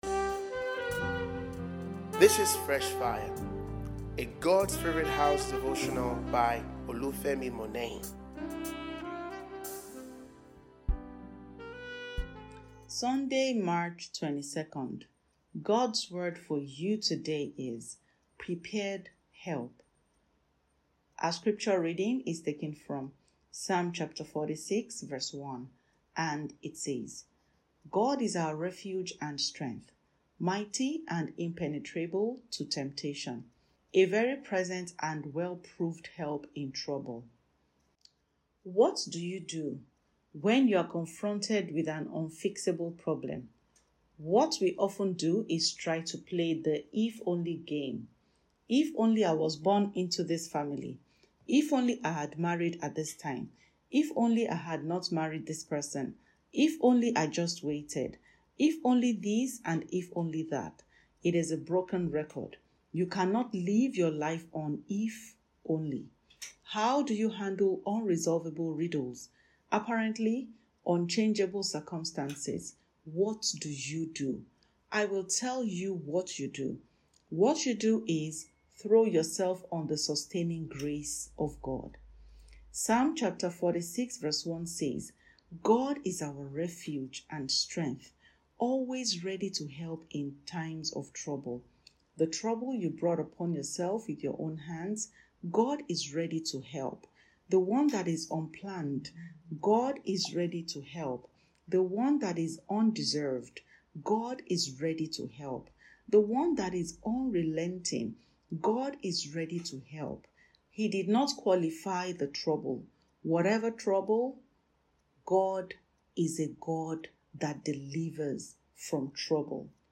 » Prepared Help Fresh Fire Devotional